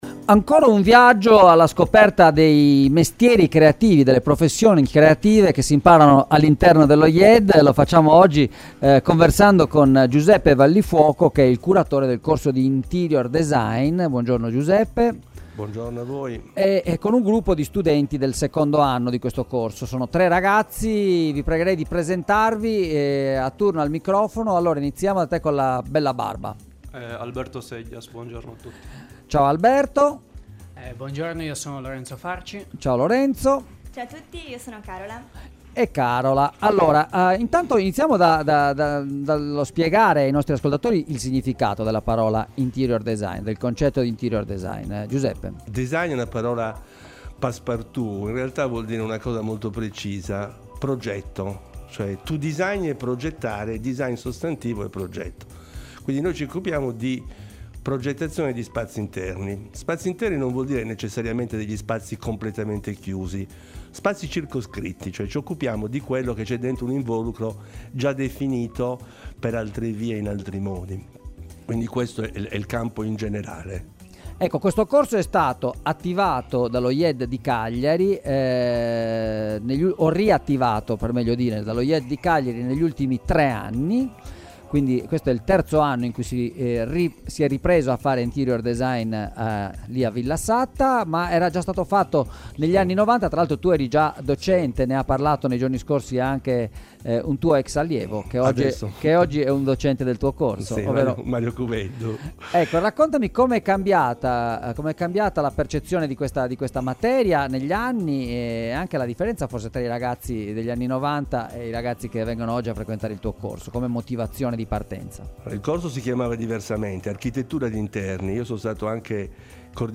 e con una delegazione di studenti del corso di “Interior design” ASCOLTA L'INTERVISTA